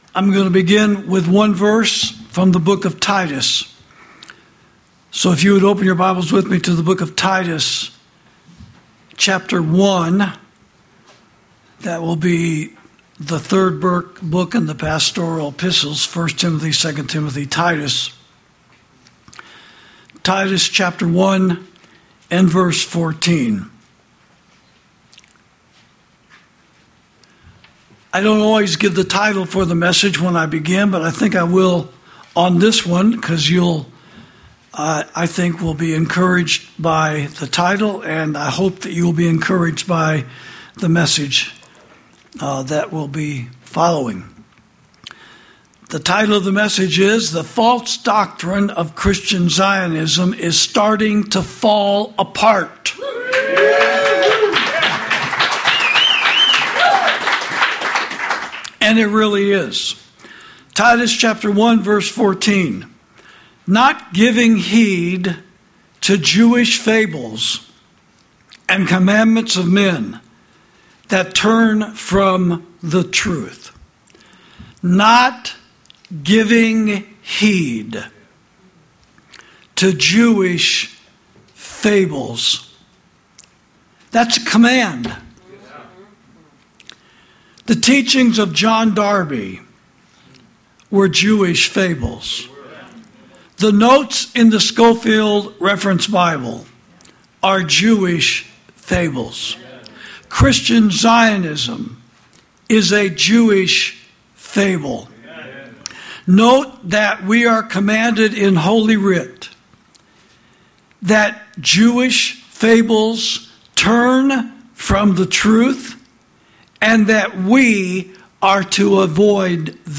Sermons > The False Doctrine Of Christian Zionism Is Starting To Fall Apart